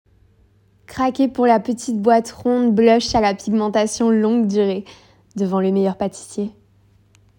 Audio casting